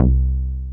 BASS XLOW.wav